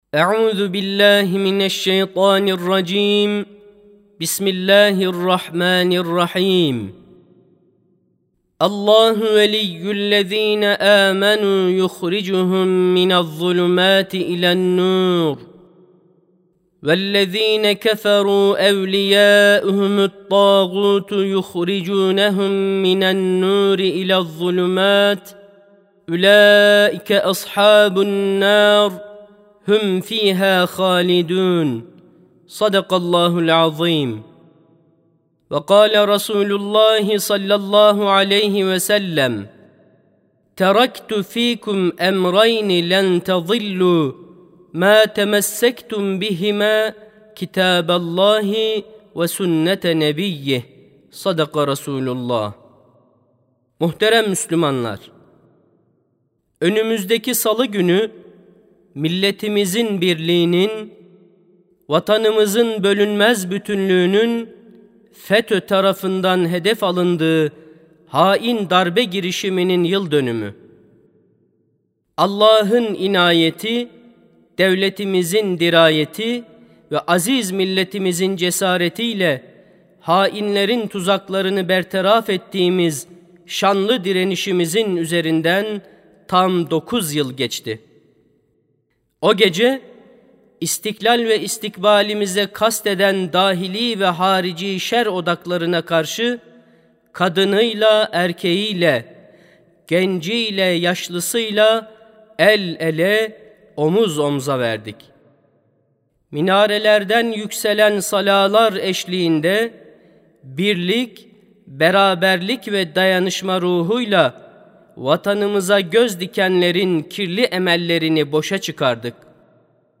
Sesli Hutbe (Sahih Dini Bilginin Önemi).mp3